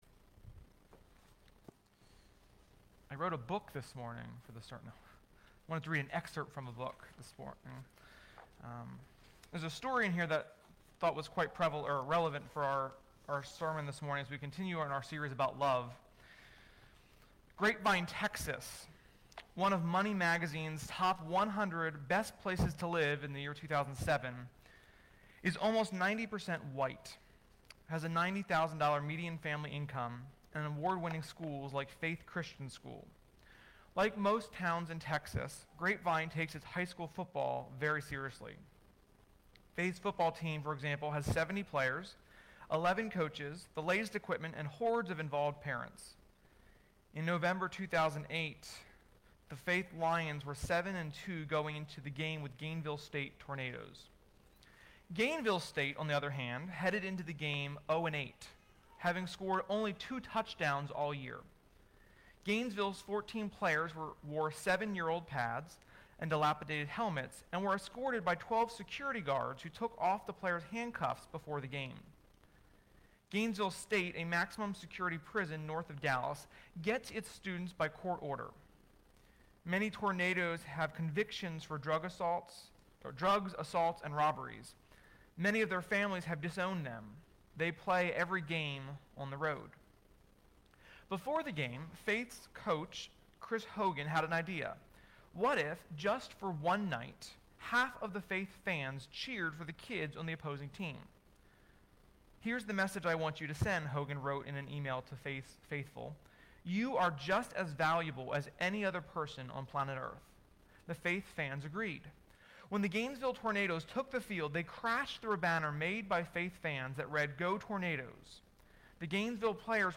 Sermon-6.28.20.mp3